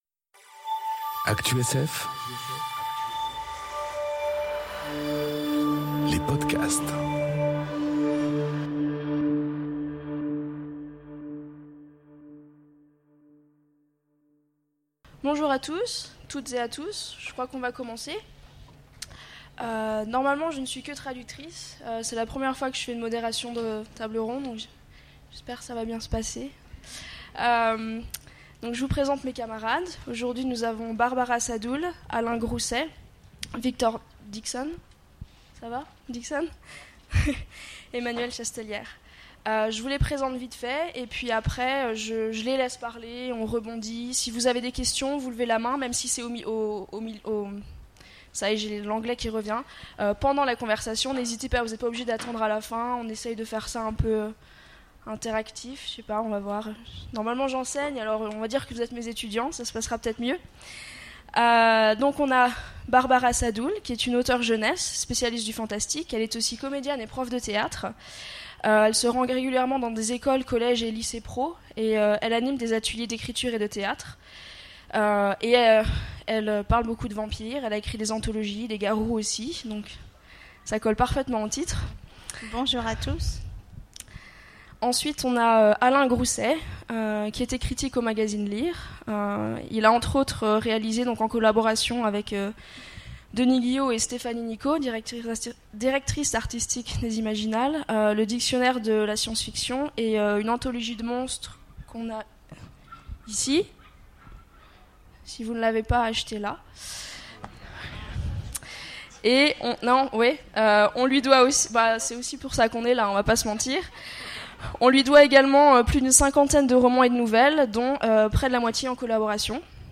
Conférence Vampires, loups-garous et autre créatures : salut les monstres ! enregistrée aux Imaginales 2018